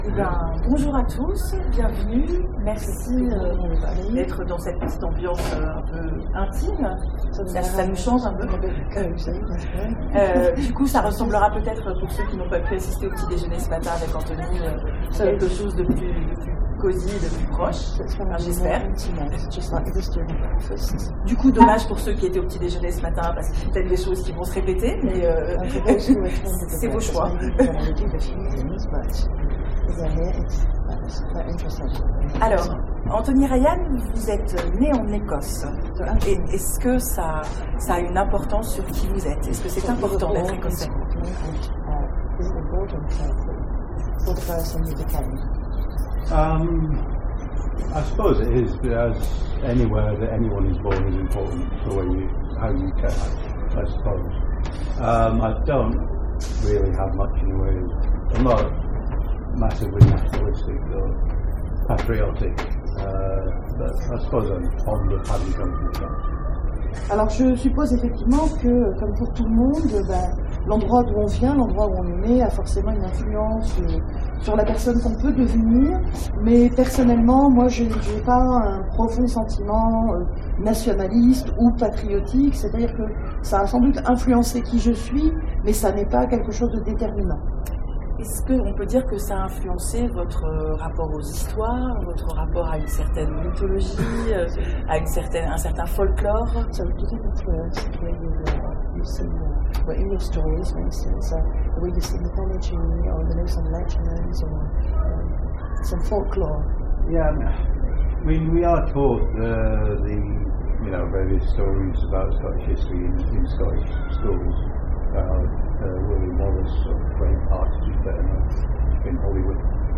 Imaginales 2016 : Entretien avec… Anthony Ryan
Anthony Ryan Télécharger le MP3 à lire aussi Anthony Ryan Genres / Mots-clés Rencontre avec un auteur Conférence Partager cet article
Imaginales_2016_conference_anthony_ryan_ok.mp3